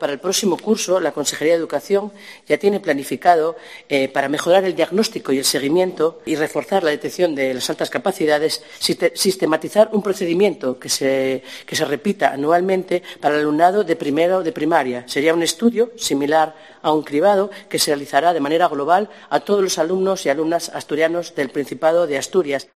Lydia Espina explica el estudio que se realizará a partir del próximo curso